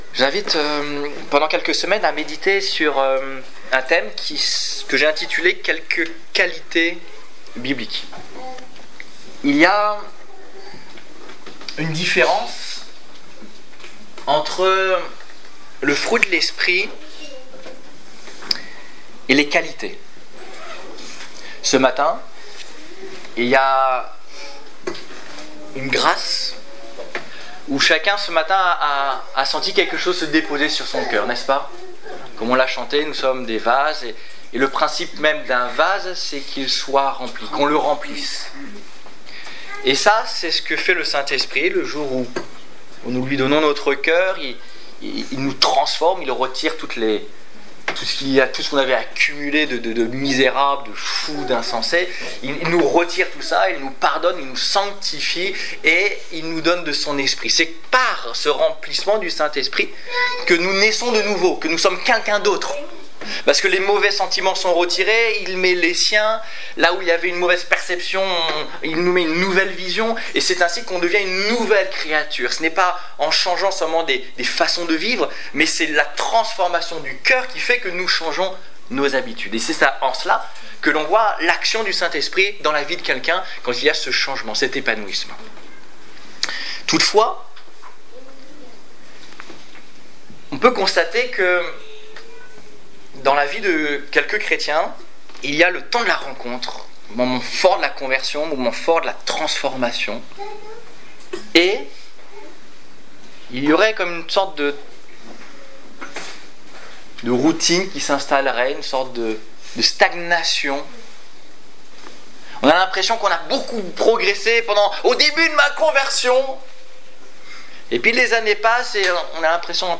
Quelques qualités bibliques - La sagesse (1) Détails Prédications - liste complète Culte du 6 septembre 2015 Ecoutez l'enregistrement de ce message à l'aide du lecteur